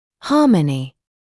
[‘hɑːmənɪ][‘хаːмэни]гармония; сбалансированность